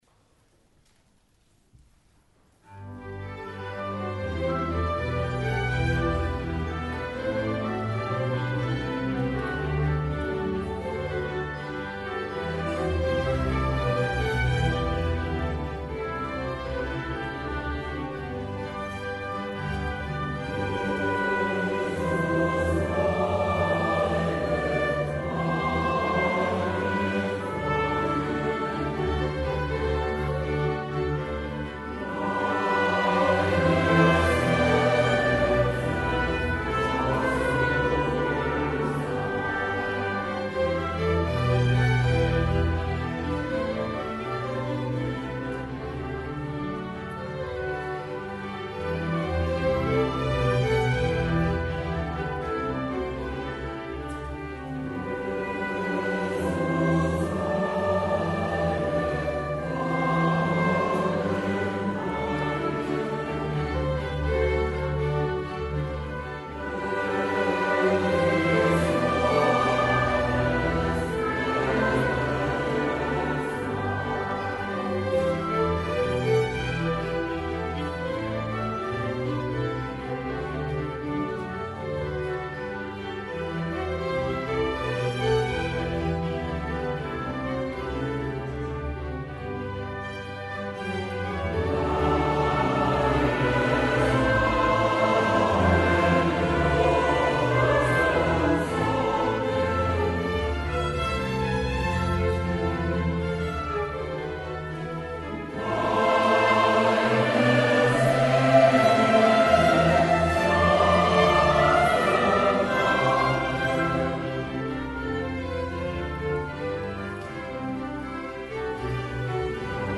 S. Gaudenzio church choir Gambolo' (PV) Italy
CAMERATA TICINENSIS
in formato audio MP3 di alcuni branii  del concerto